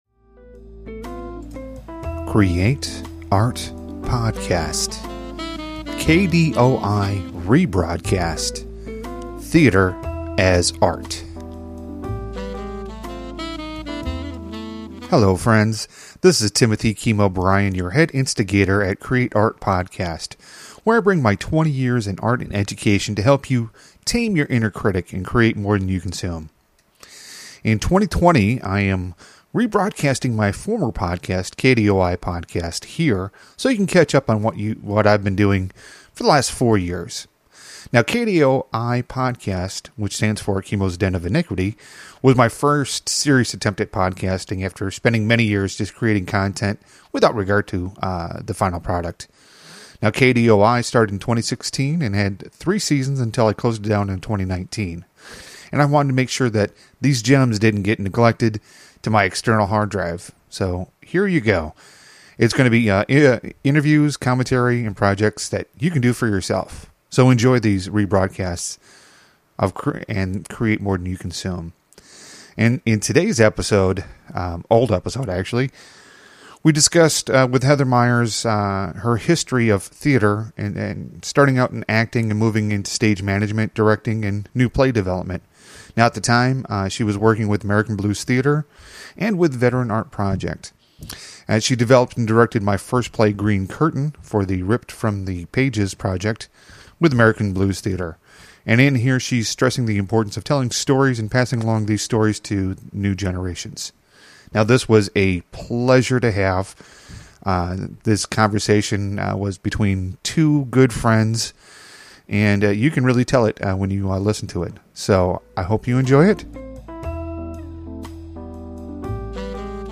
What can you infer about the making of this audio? She stresses the importance of telling stories and passing along those stories to new generations. This was a very enlightening conversation about our experiences in theater and as you can tell it was a conversation between two friends.